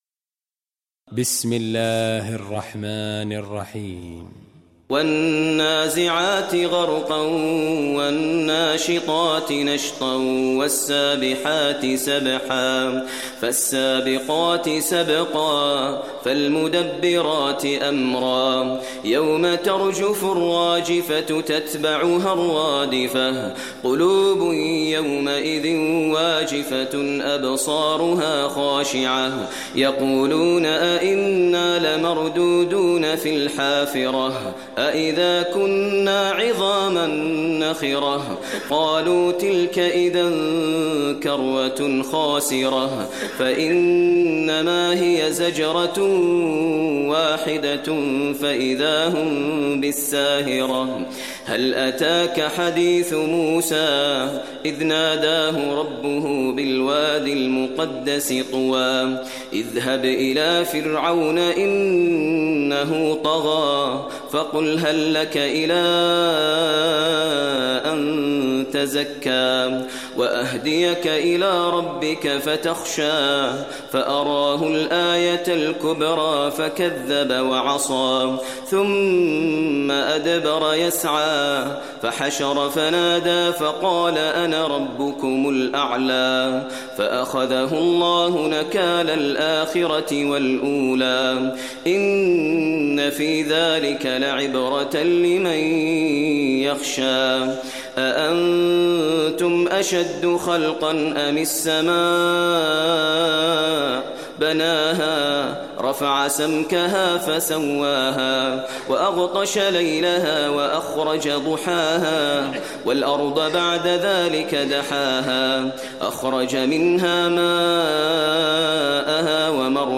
Surah An Naziat Recitation by Maher al Mueaqly
Surah Naziat, listen online mp3 tilawat / recitation in Arabic recited by Imam e Kaaba Sheikh Maher al Mueaqly.